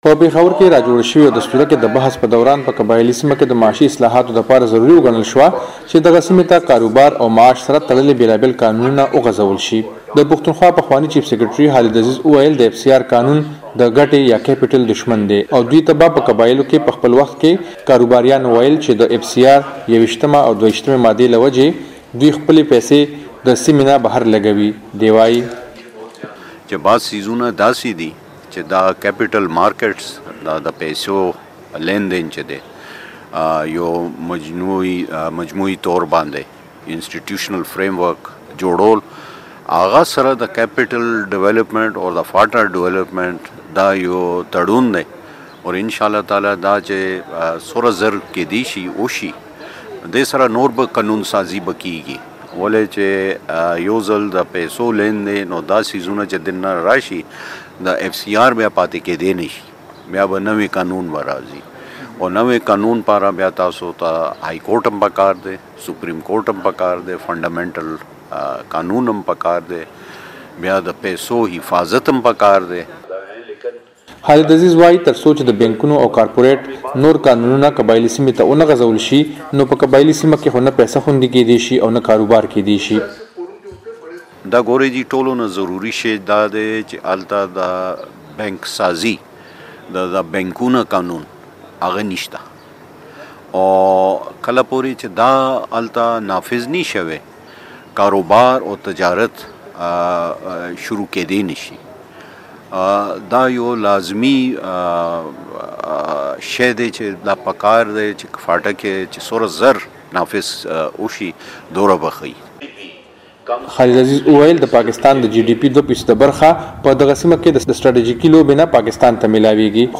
د پاکستان په قبائیلي سیمو کې موجود د انگریز د وخت لانجمن قانون ایف سي ار نه یوازې د سیمې د سیاسي بلکه د اقتصادي پرمختگ لار هم نیولي ده، دا خبره په پیښور کې په یوه غونډه کې ماهرینو وکړه.